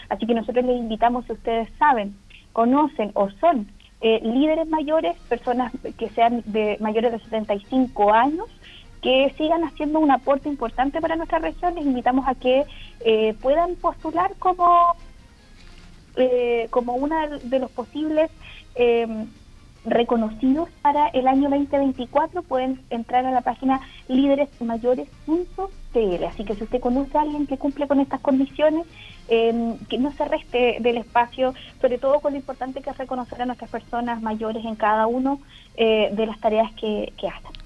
La autoridad invitó a la comunidad para postular a sus lideres de la tercera edad, pues no busca solo valorar a las personas y su impacto al país, sino que también generar un cambio cultural en la forma en que se visibiliza la vejez, en un contexto en donde la edad es la tercera causa de mayor discriminación en el mundo.